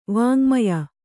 ♪ vāŋmaya